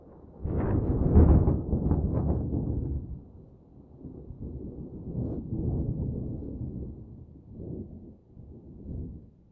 tenkoku_thunder_distant06.wav